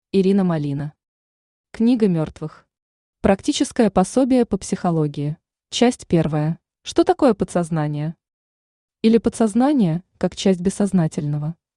Аудиокнига Книга мертвых. Практическое пособие по психологии | Библиотека аудиокниг
Практическое пособие по психологии Автор Ирина Малина Читает аудиокнигу Авточтец ЛитРес.